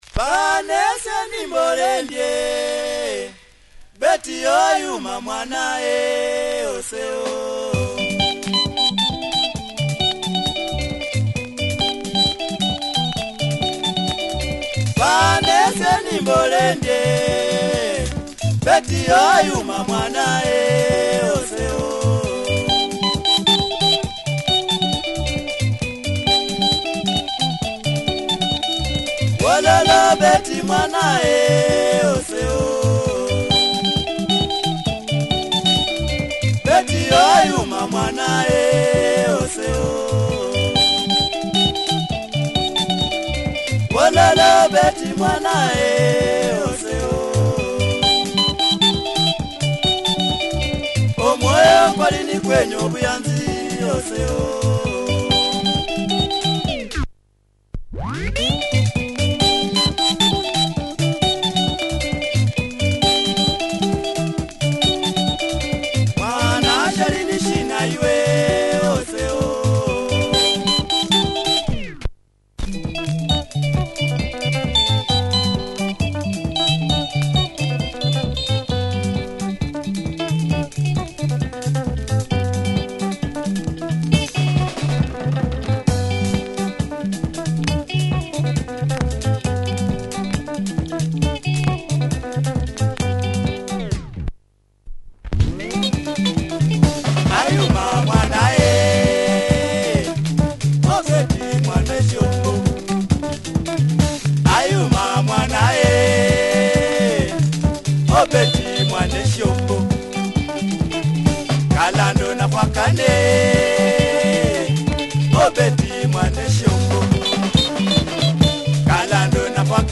Melodic luhya Benga